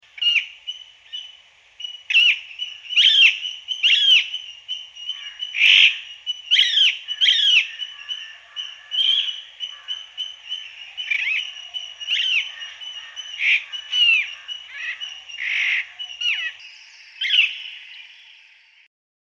قدرت جیغ زدن (۶)
میشه گفت تنها ویژگی ای از طوطی اسکندر که از ملنگو بدتره جیغ زدنشه که عمیق و گیرا‌تره. اگه بخوایم به فرکانس جیغش امتیاز بدیم ۴ رو میگیره ولی از لحاظ گوش خراش بودنش به ۱۰-۸ هم می‌رسه!